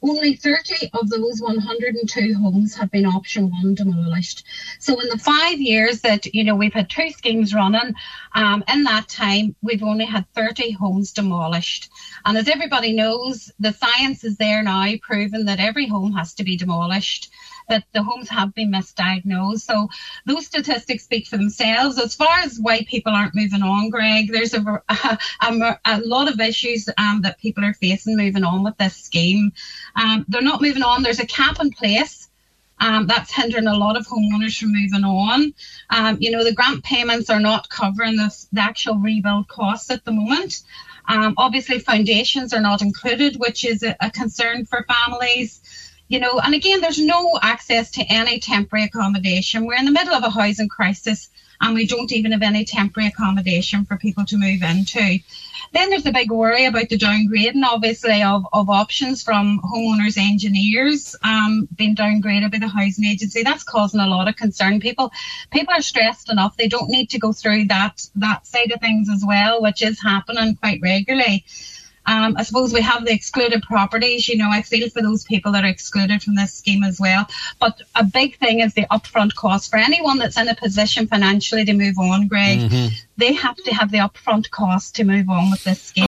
this morning’s Nine ‘Til Noon Show